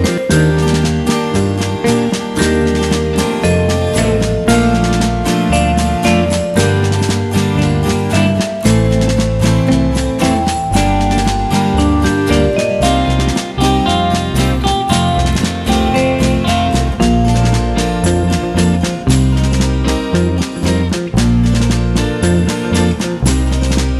no Backing Vocals Soundtracks 2:21 Buy £1.50